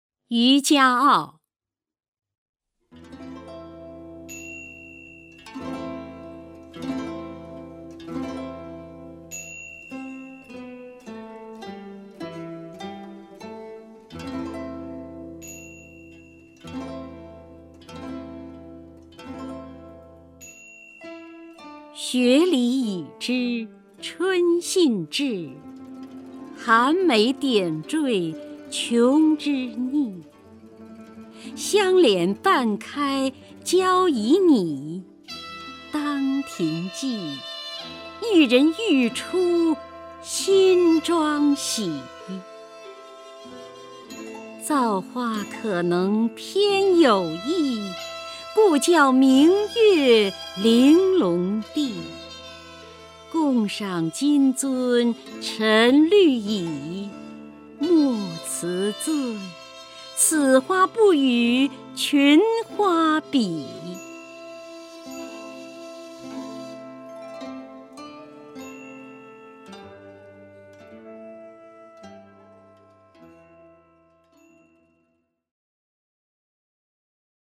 首页 视听 名家朗诵欣赏 姚锡娟
姚锡娟朗诵：《渔家傲·雪里已知春信至》(（南宋）李清照)
YuJiaAoXueLiYiZhiChunXinZhi_LiQingZhao(YaoXiJuan).mp3